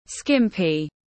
Chật ních tiếng anh gọi là skimpy, phiên âm tiếng anh đọc là /ˈskɪm.pi/ .
Skimpy /ˈskɪm.pi/